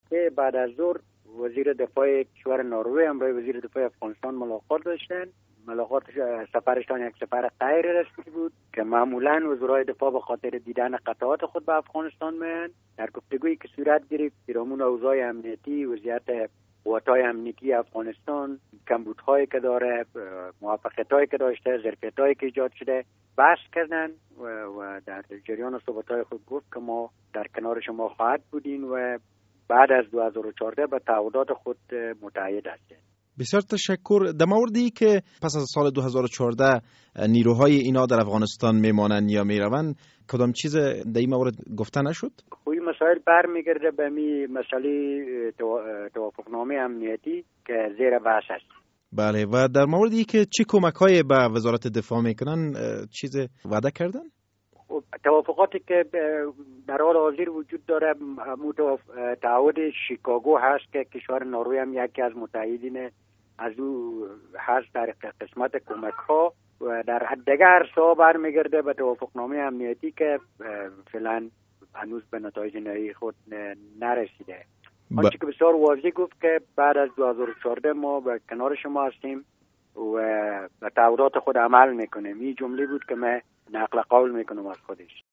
مصاحبه: وزیر دفاع ناروی در یک سفر غیر رسمی وارد افغانستان شد